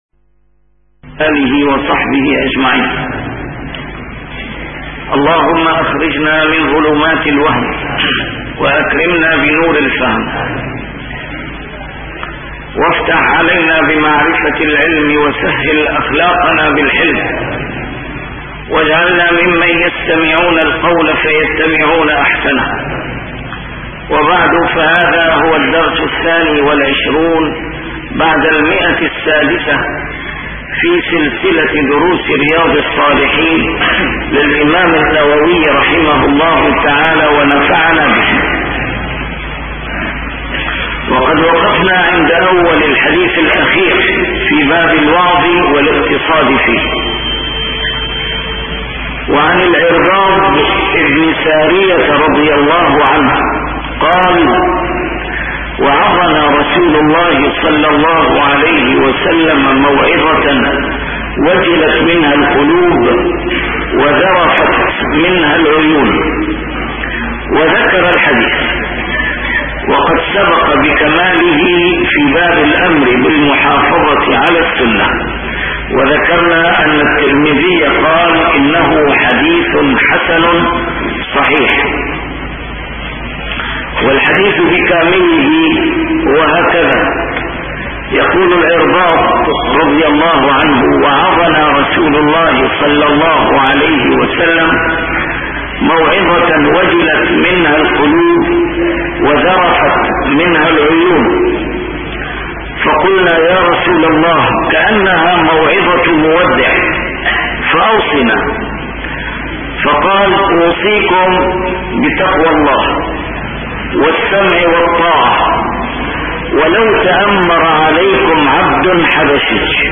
A MARTYR SCHOLAR: IMAM MUHAMMAD SAEED RAMADAN AL-BOUTI - الدروس العلمية - شرح كتاب رياض الصالحين - 622- شرح رياض الصاالحين: الوعظ والاقتصاد فيه